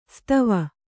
♪ stava